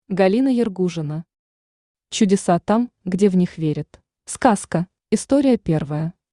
Аудиокнига Чудеса там, где в них верят | Библиотека аудиокниг
Aудиокнига Чудеса там, где в них верят Автор Галина Ергужина Читает аудиокнигу Авточтец ЛитРес.